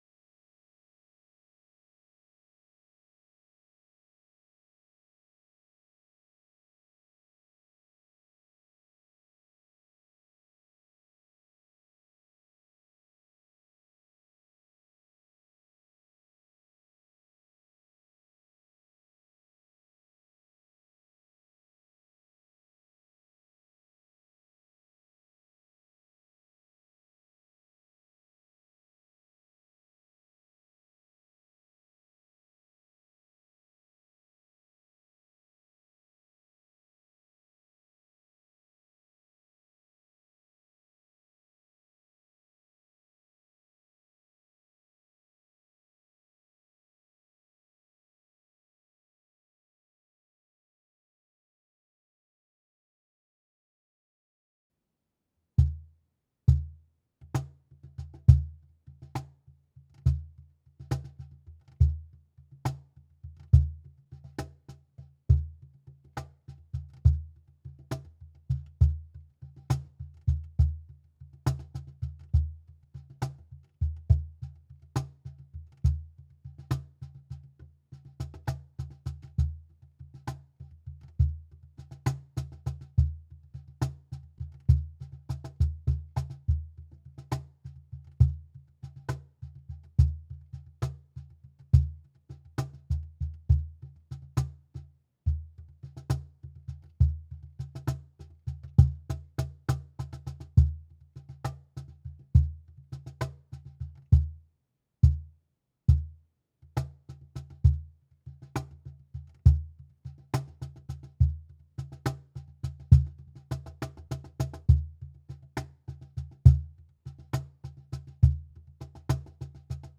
Toward Tomorrow - Cajon - Mono.wav